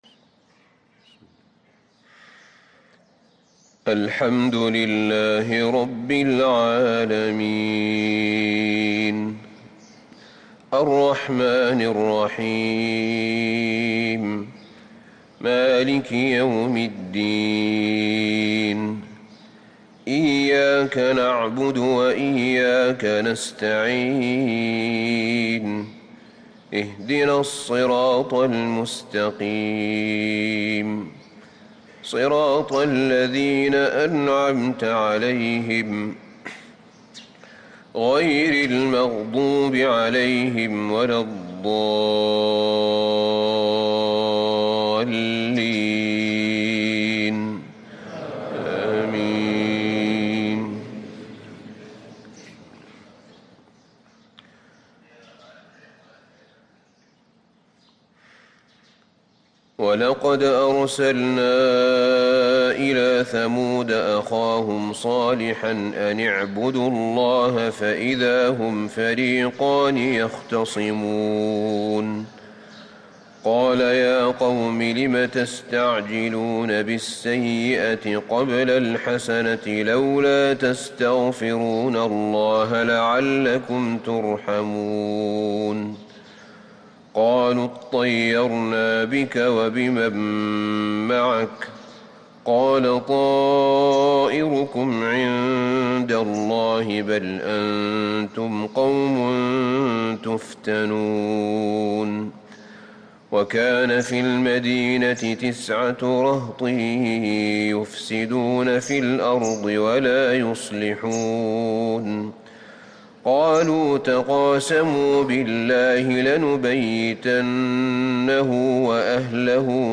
صلاة الفجر للقارئ أحمد بن طالب حميد 24 رجب 1444 هـ